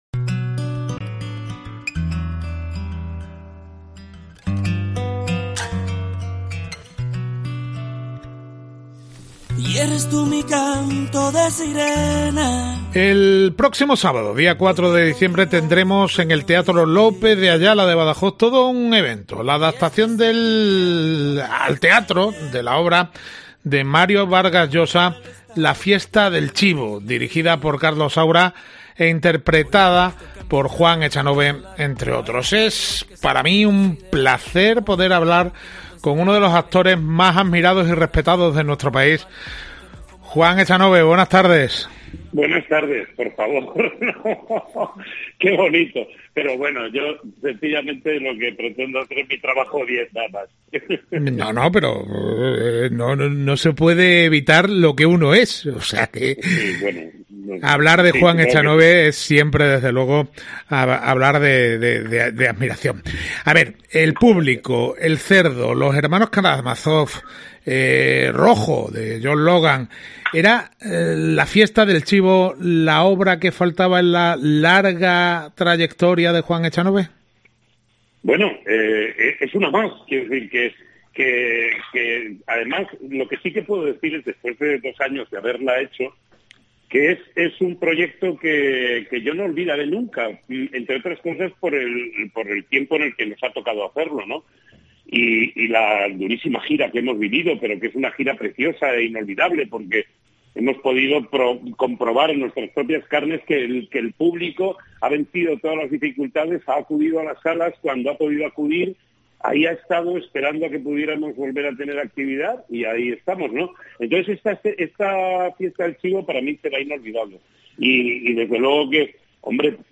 Hablamos con Juan Echanove que el Sábado 4 trae hasta el López de Ayala La Fiesta del Chivo